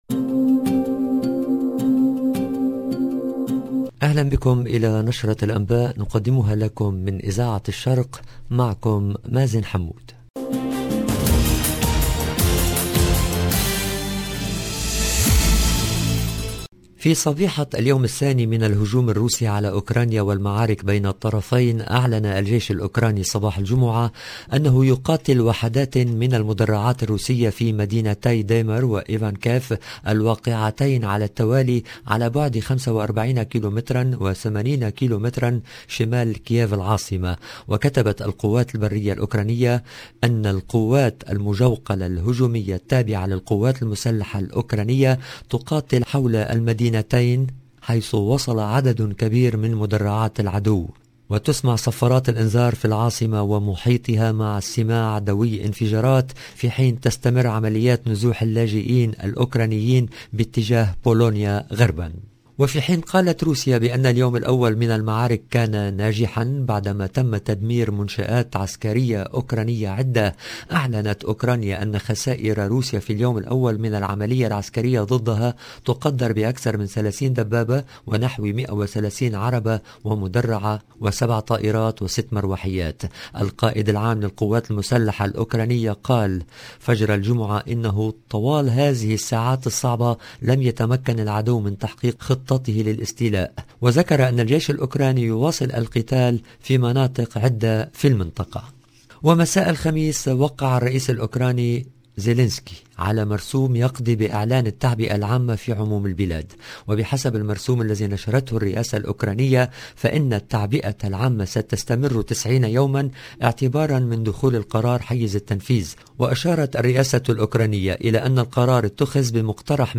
LE JOURNAL DU SOIR EN LANGUE ARABE DU 25/02/22